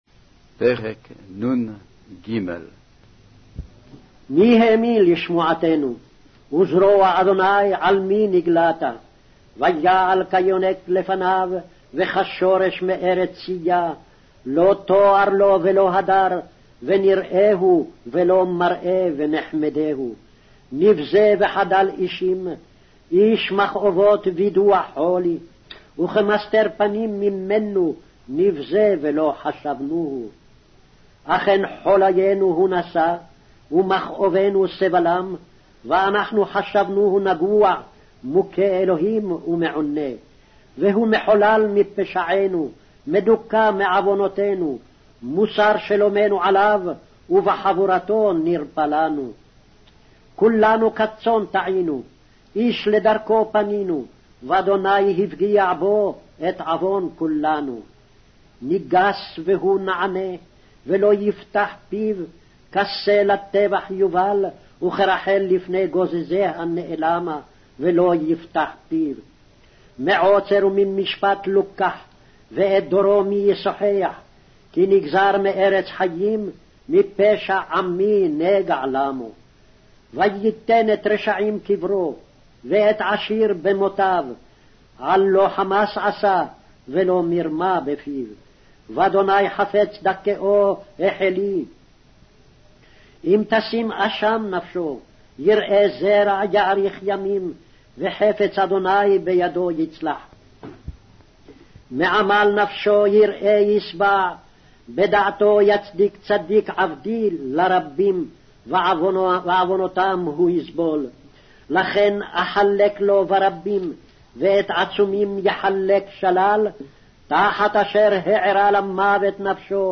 Hebrew Audio Bible - Isaiah 2 in Ecta bible version